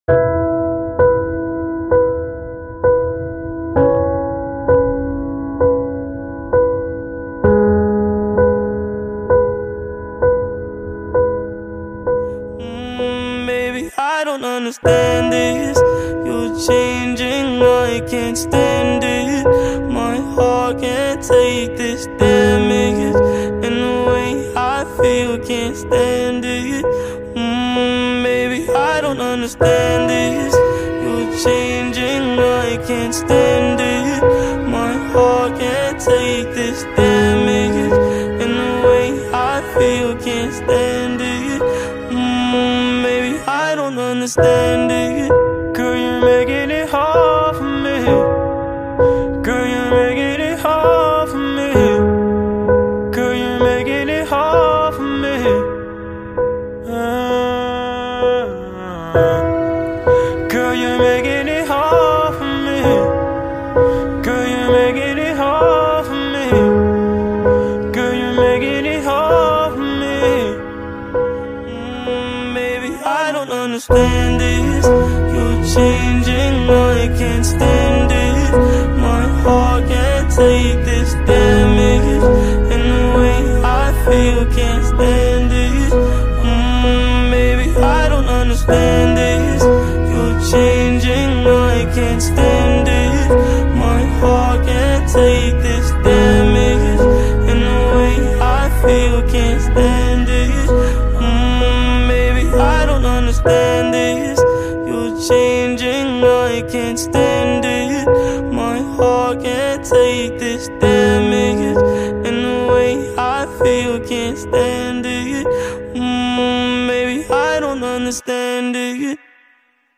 hip hop
Piano